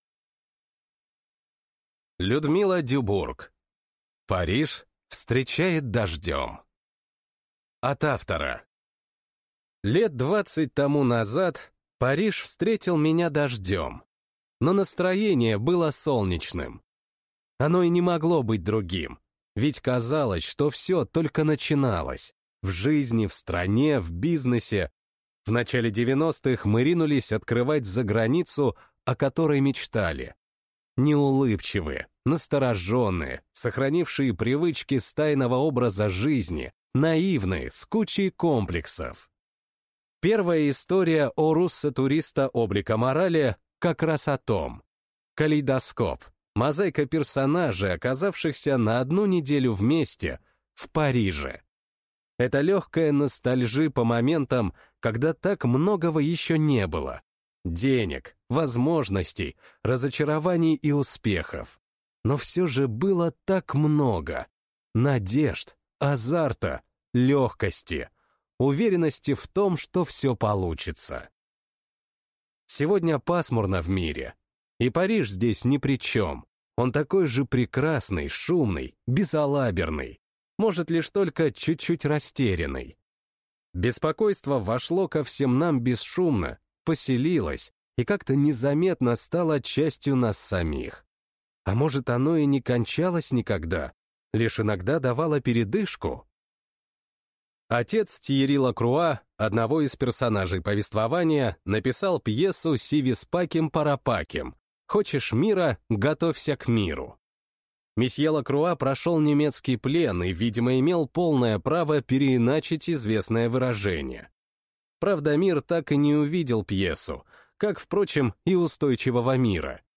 Аудиокнига Париж встречает дождем | Библиотека аудиокниг